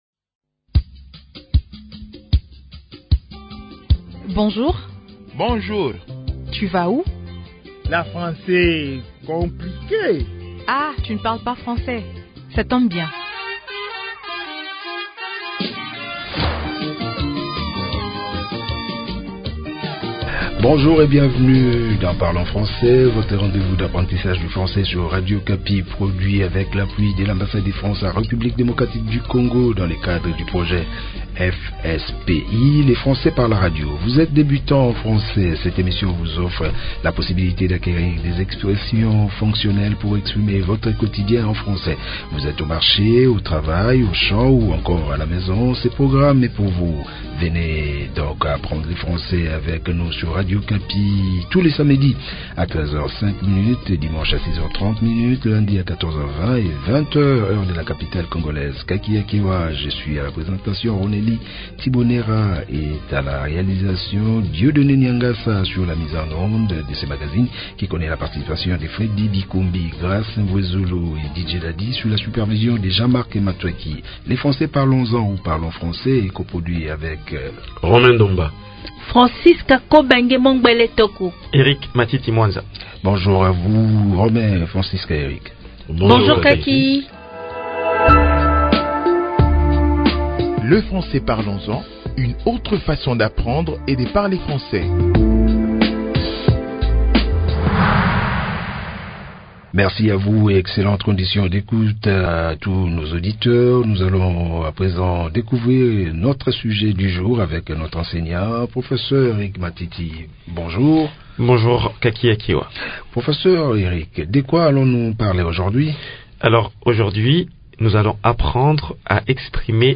Nous sommes heureux de vous faire parvenir cette nouvelle leçon sur la manière d’exprimer votre colère en français. Ce nouvel épisode vous offre des expressions simples et pratiques pour parler de votre colère sans blesser votre interlocuteur.